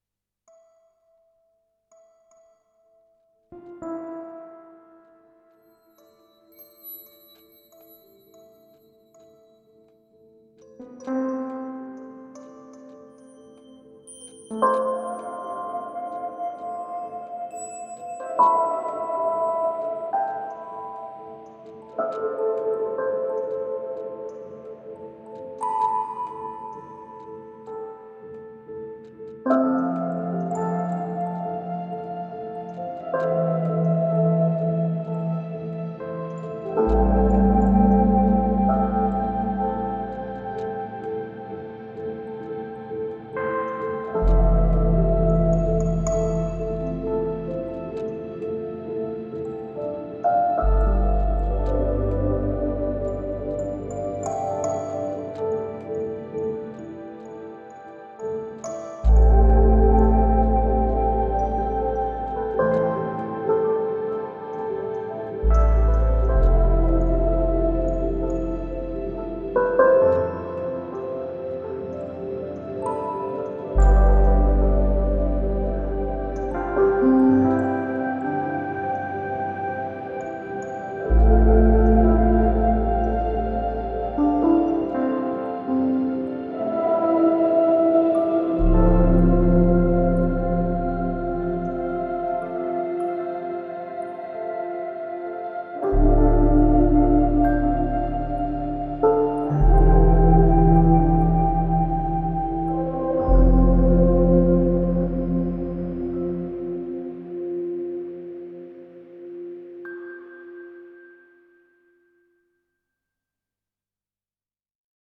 Textural layers, tones and natural atmosphere.